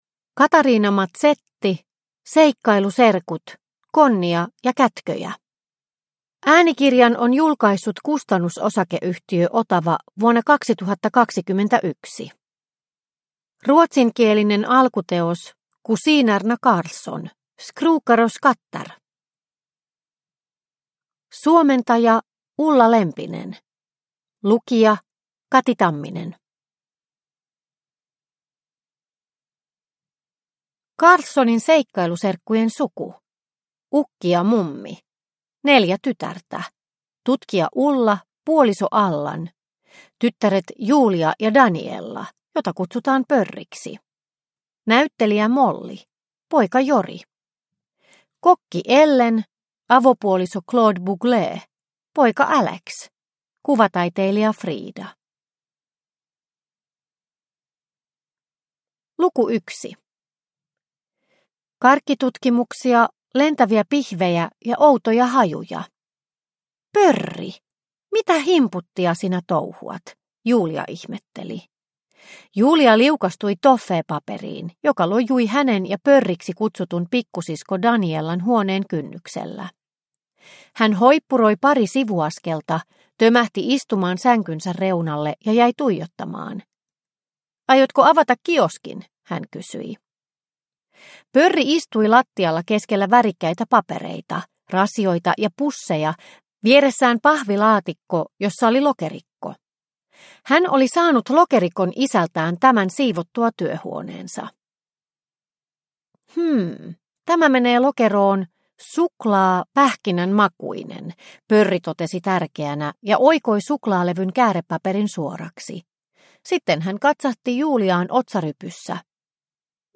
Konnia ja kätköjä – Ljudbok – Laddas ner